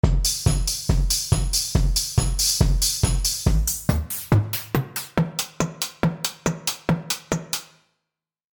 Dj Drum Transition